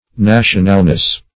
Search Result for " nationalness" : The Collaborative International Dictionary of English v.0.48: Nationalness \Na"tion*al*ness\, n. The quality or state of being national; nationality.